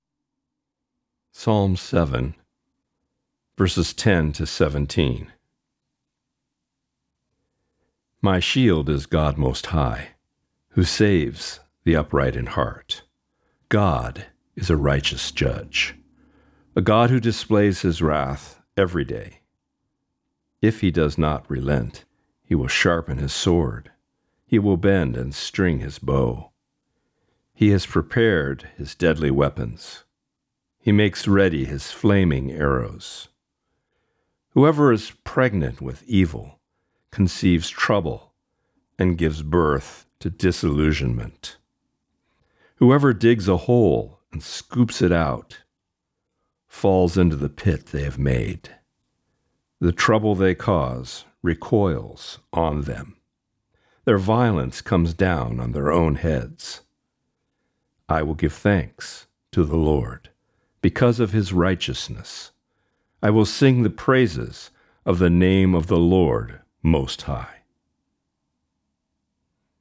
Reading: Psalm 7:10-17